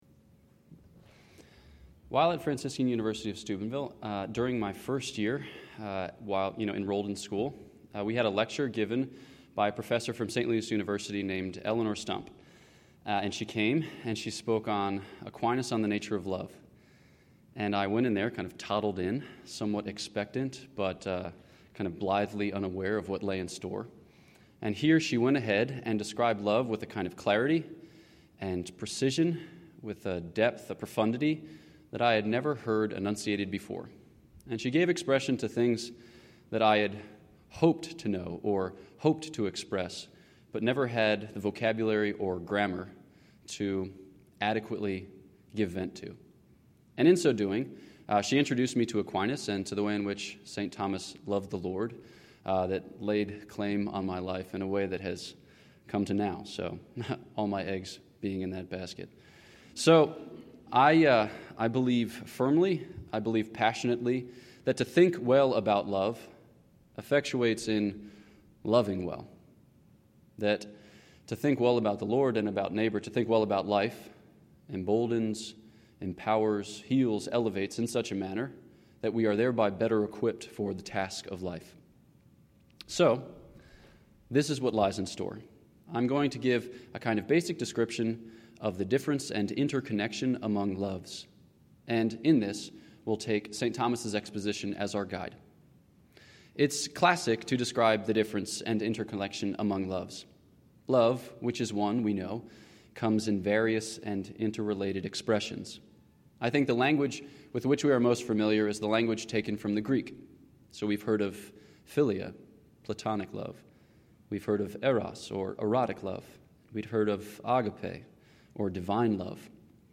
This lecture was given at the University of Kansas on 30 September 2019.